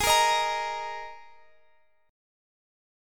Listen to G#sus2 strummed